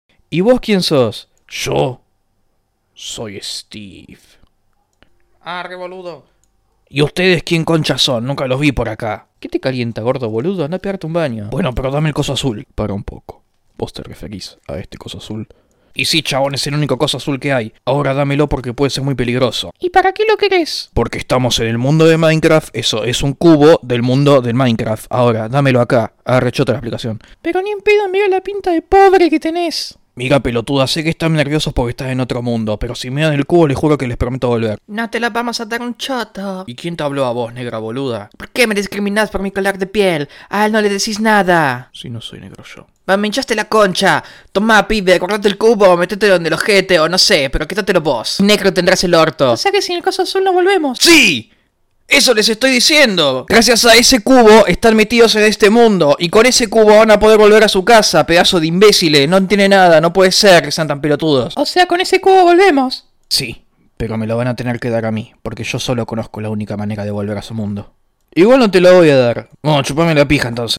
Un Doblaje De Minecraft Argentino/